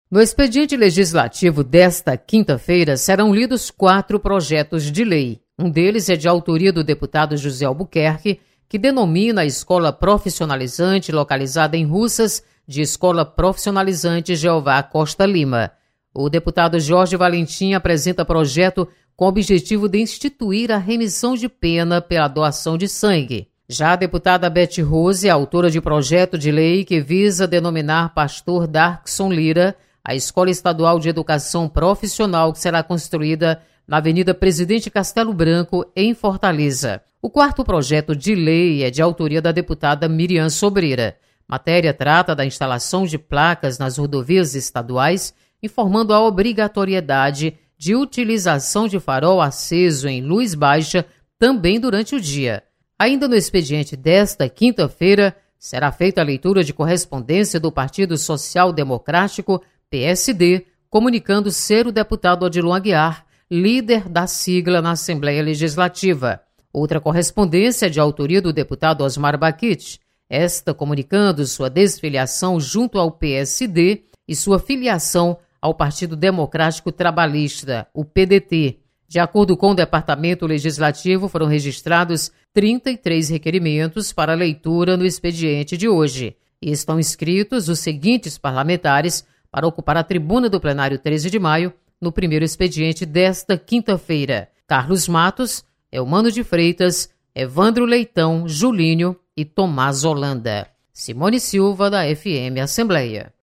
com a repórter